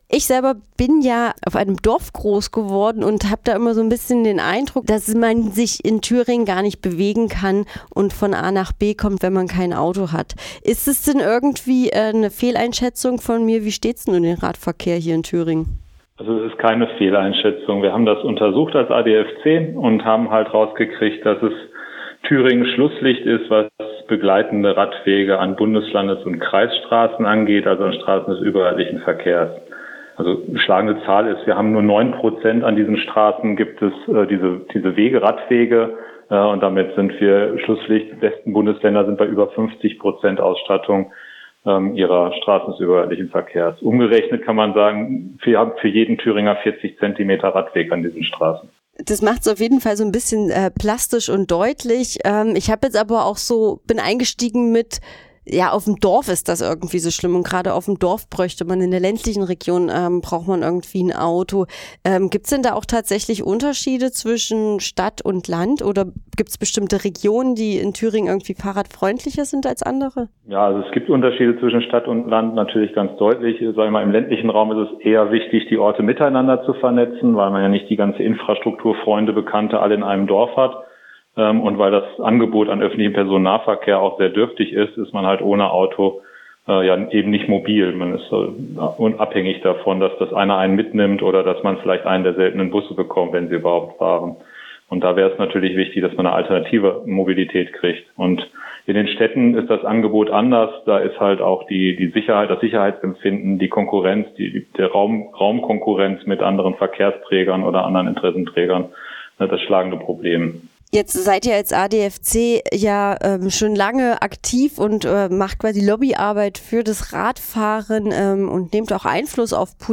Über die Zukunft des Radverkehrs in Thüringen | Interview